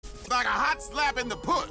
Sound Buttons: Sound Buttons View : HOT SLAP